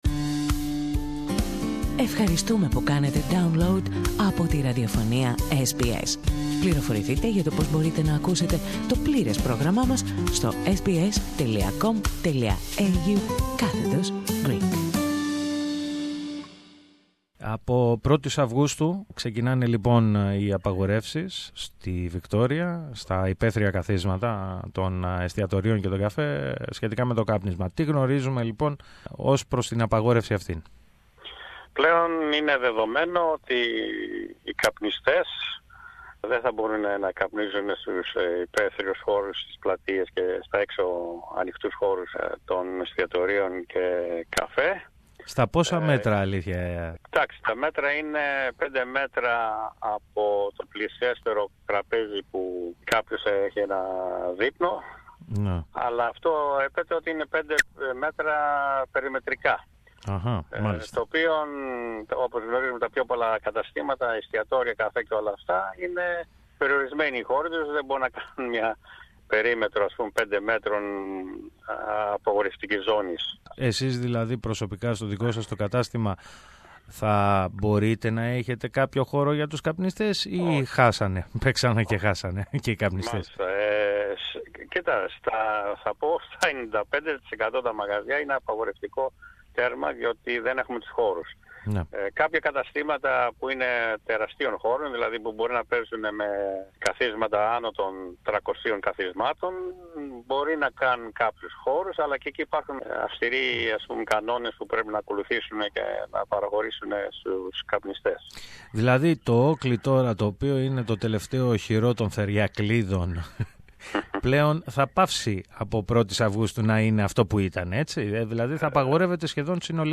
Ακούμε τη συνομιλία